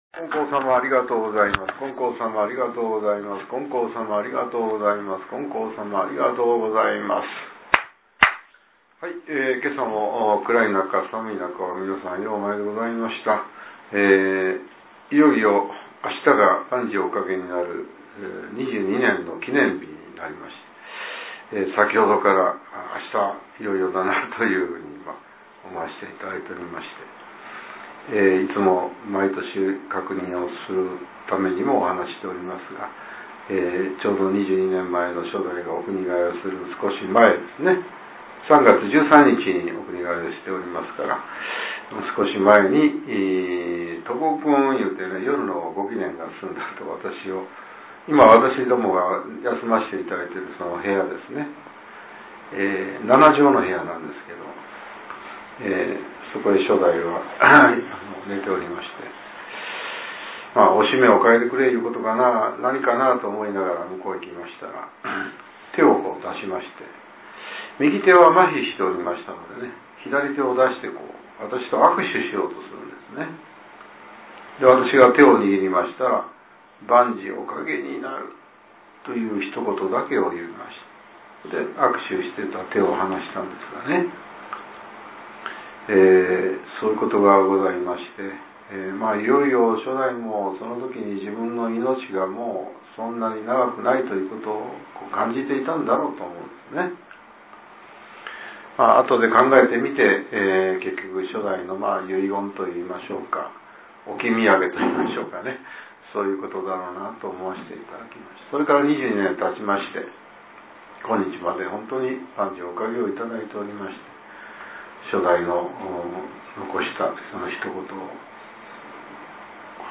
令和７年２月１７日（朝）のお話が、音声ブログとして更新されています。